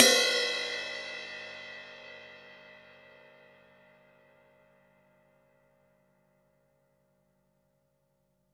Index of /90_sSampleCDs/Total_Drum&Bass/Drums/Cymbals
ride_cym5.wav